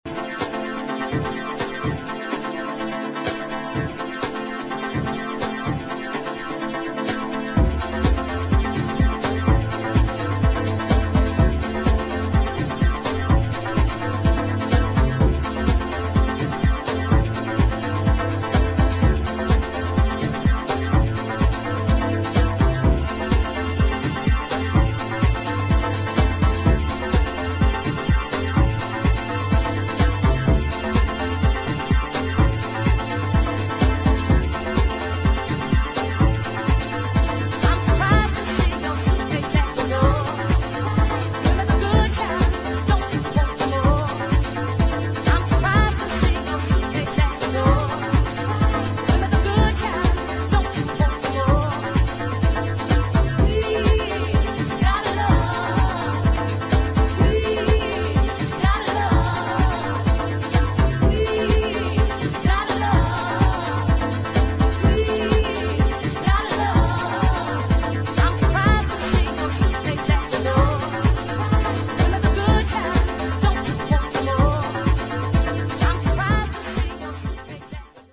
[ HOUSE / GARAGE HOUSE ]